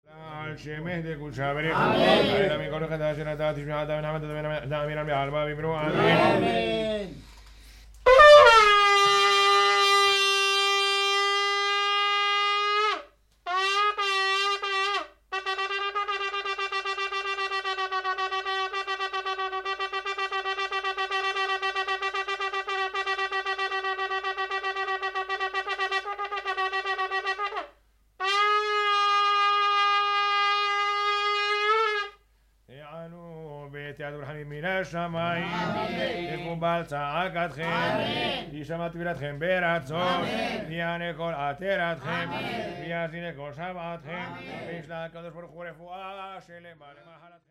Shofar Acoustics
shofar3.mp3